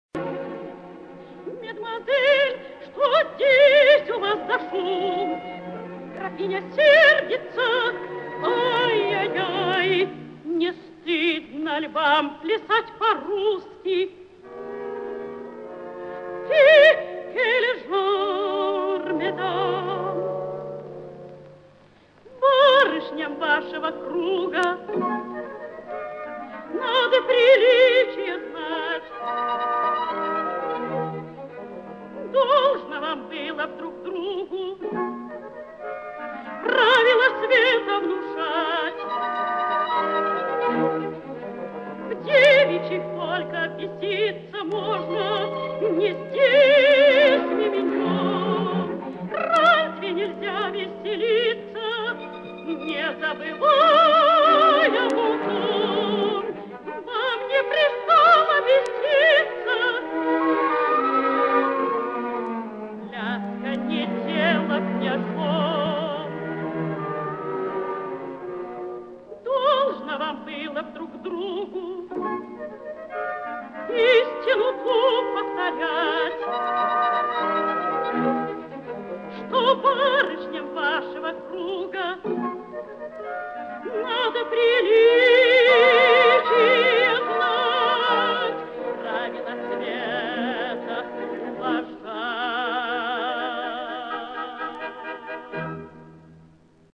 меццо-сопрано
Опера «Пиковая дама». Ариозо Гувернантки. Оркестр Большого театра. Дирижёр С. А. Самосуд.